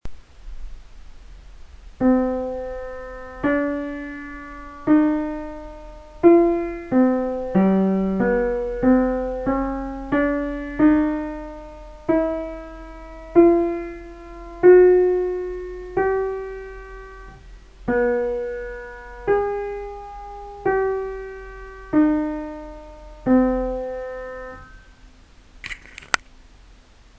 You can listen to the written voice here